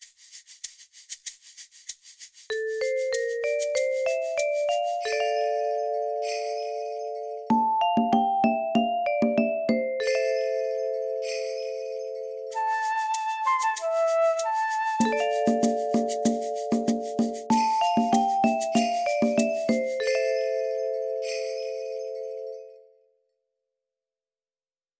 Each time the train passes the play station, it reads out the chosen motifs and reveals the composition.
compositrain-demo.wav